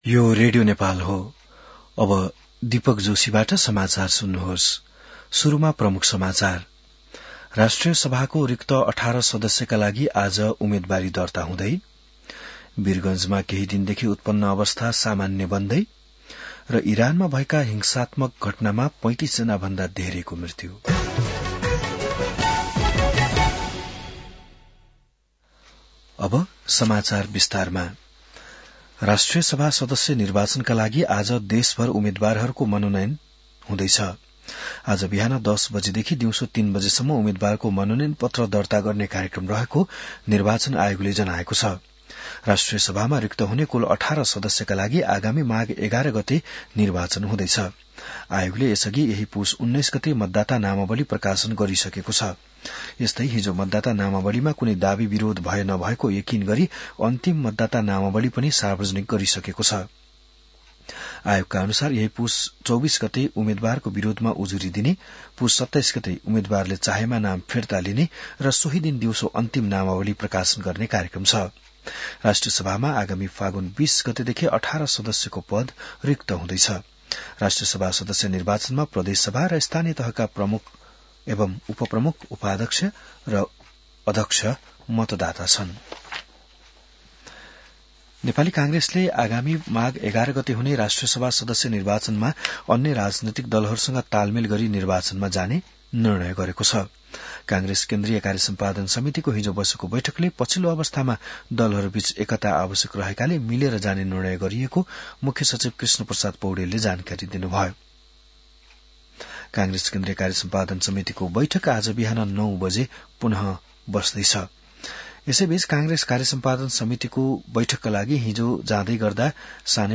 बिहान ९ बजेको नेपाली समाचार : २३ पुष , २०८२